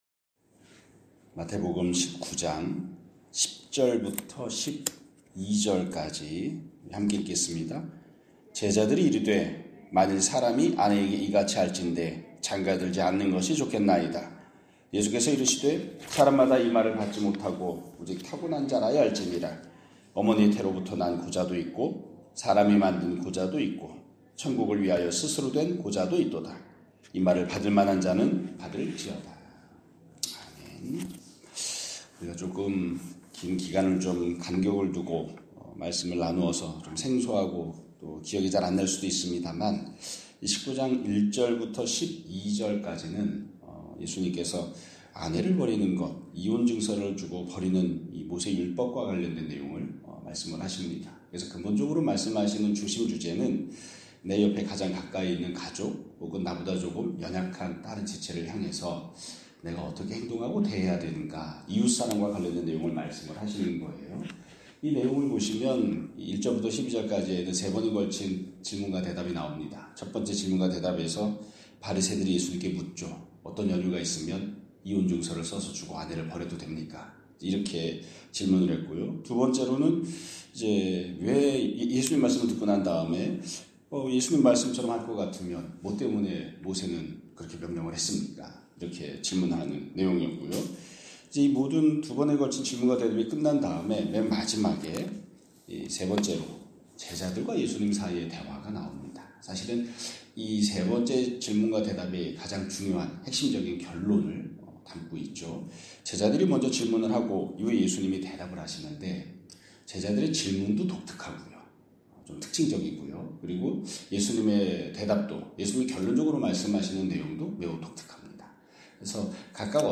2026년 1월 5일 (월요일) <아침예배> 설교입니다.